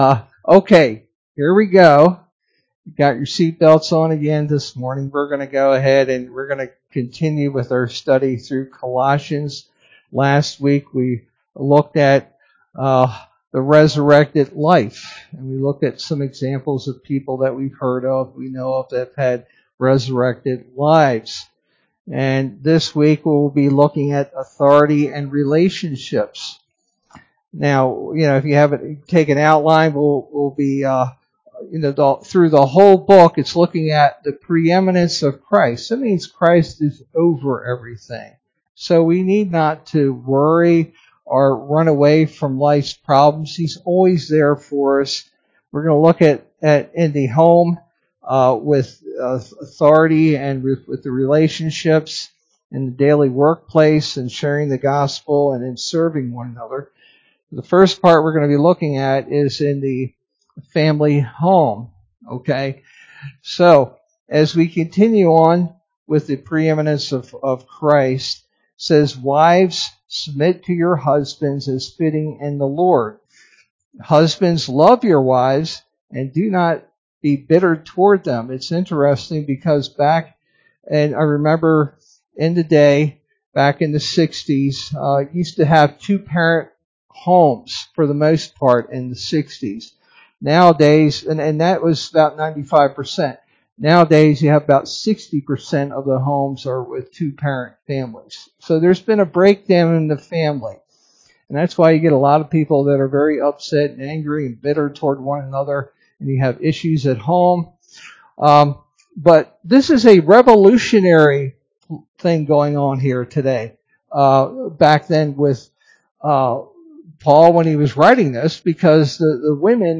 Sermon verse: Colossians 3:18-4:17